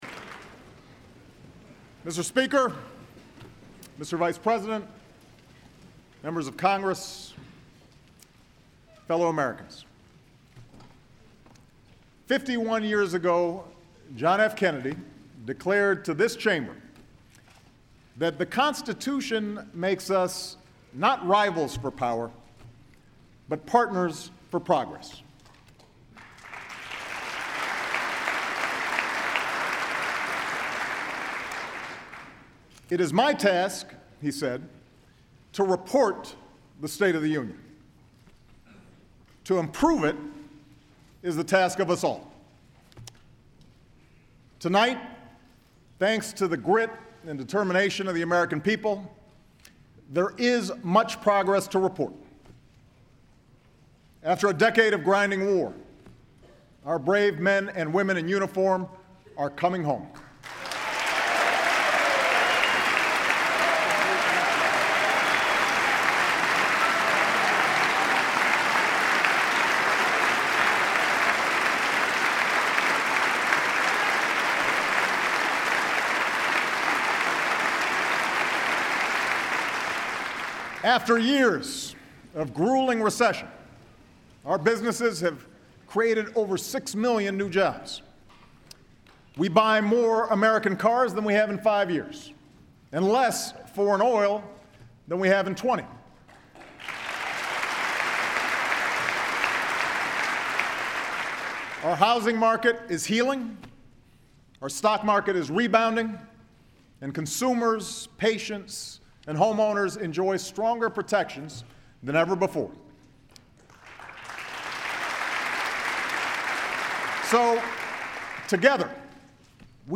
U.S. President Barack Obama delivers the annual State of the Union address
President Obama delivers the annual State of the Union address before a joint session of Congress. Obama outlines priorities for legislative action proposing a variety of ways to accelerate the economic recovery including universal preschool and making college more affordable.
He calls for changes to voting processes and an end to extraordinarily long waits to cast a ballot. Obama closes by invoking the memory of those affected by gun violence stirring the crowd by declaring that gun control proposals deserve consideration in Congress and the victims "deserve a vote."